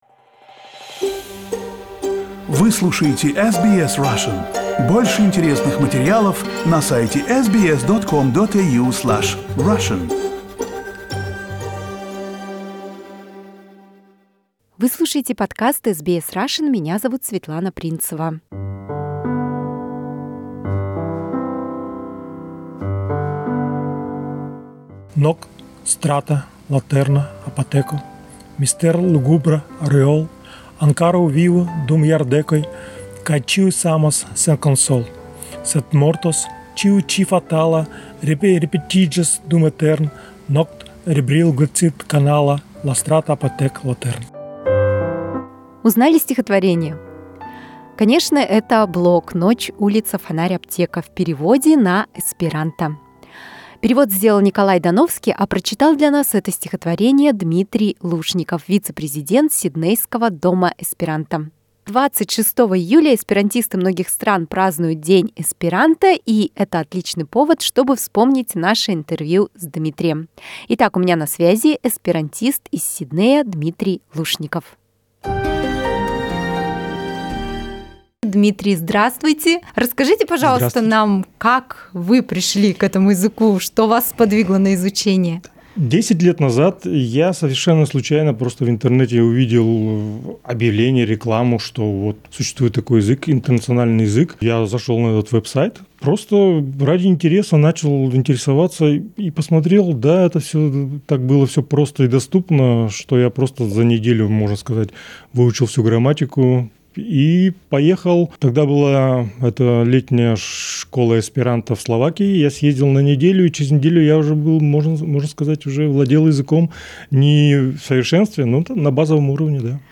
Из интервью